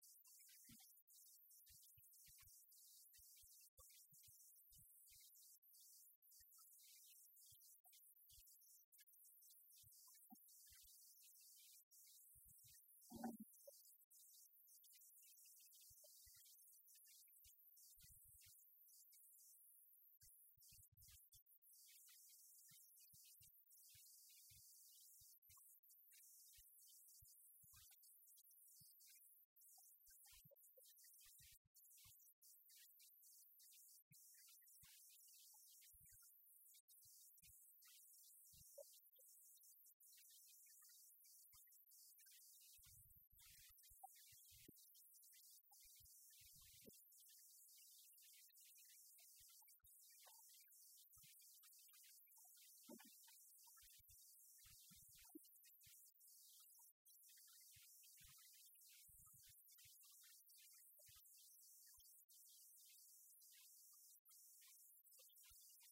António Ponte, Diretor Regional de Cultura do Norte, esteve presente para ratificar o documento, e explicou em que consiste.